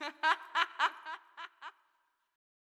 metro laugh 1.wav